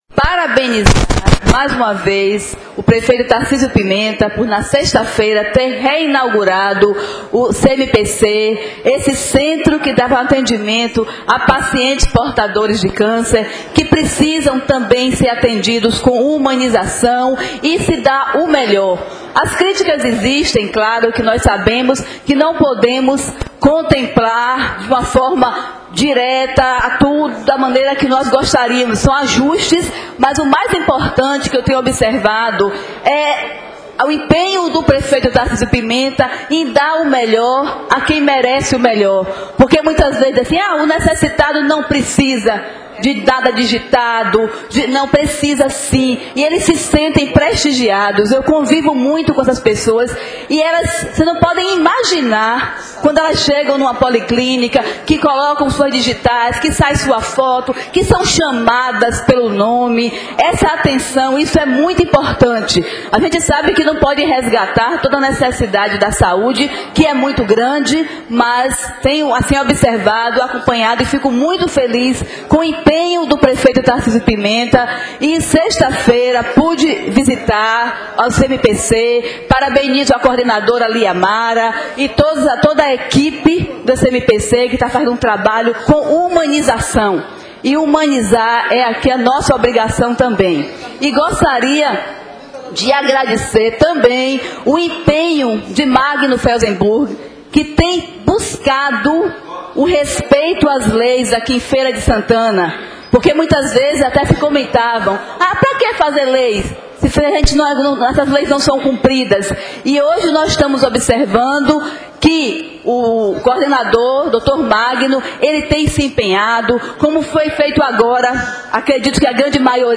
A vereadora Gerusa Sampaio (PDT) elogia ações do Procon através do diretor Magno Felzemburg e parabeniza o prefeito Tarcízio Pimenta.